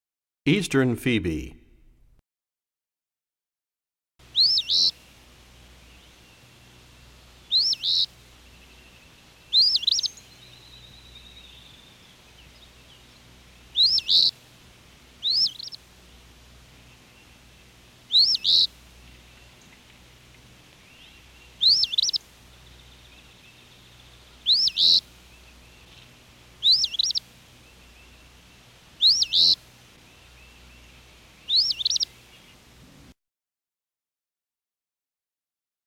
35 Eastern Phoebe.mp3